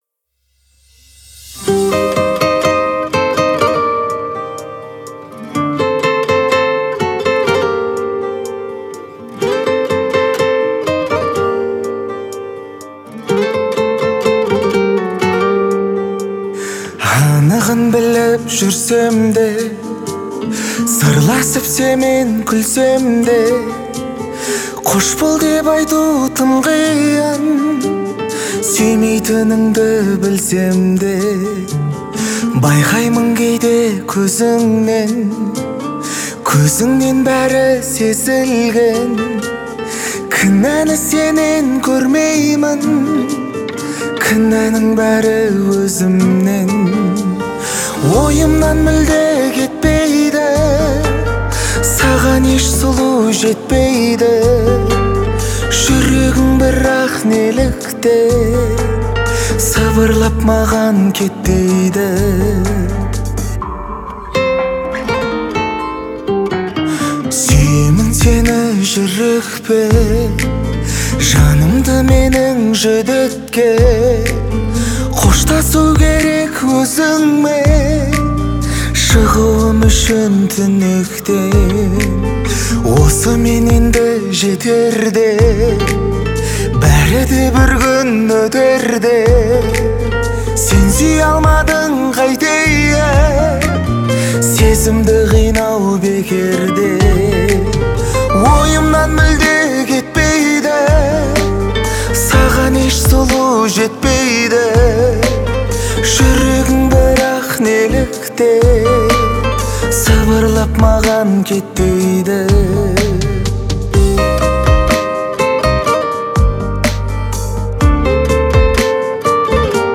это трек в жанре казахского поп